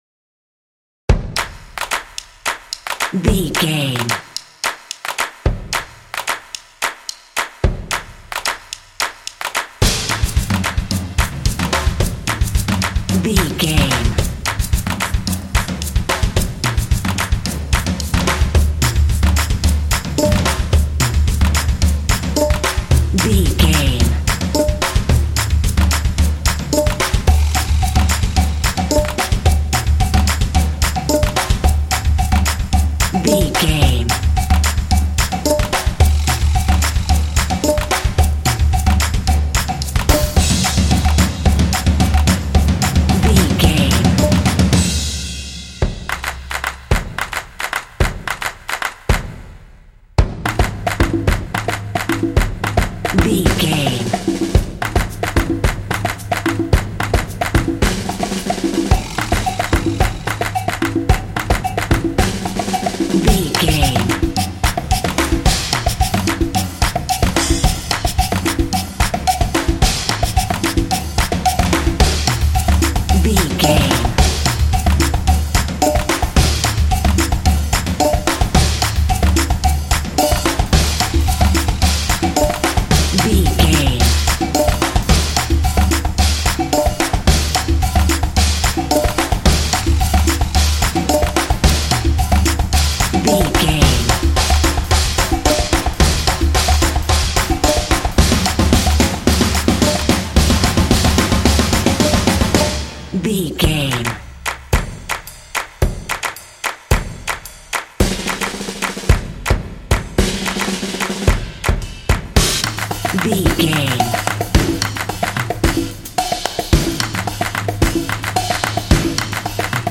Epic / Action
Atonal
driving
determined
drums
percussion
drumline